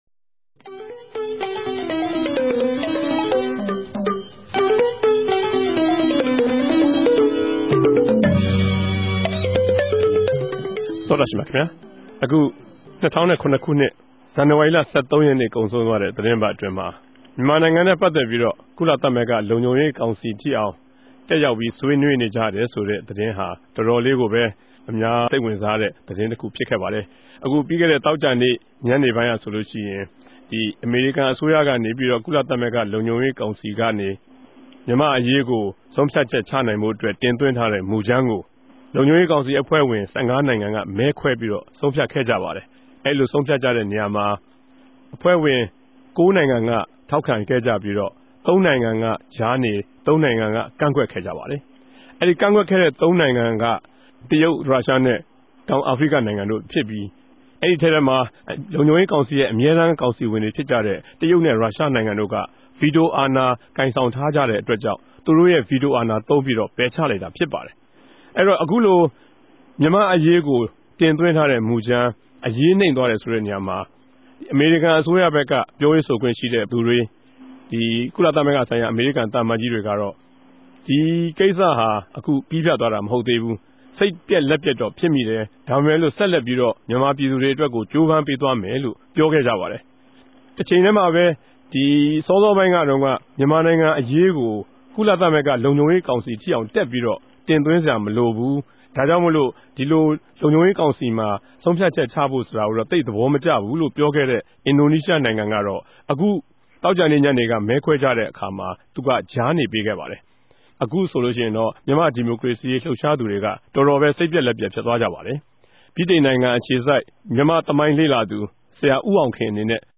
တယ်လီဖုန်းနဲႛ ဆက်သြယ် ဆြေးေိံြးထားပၝတယ်၊၊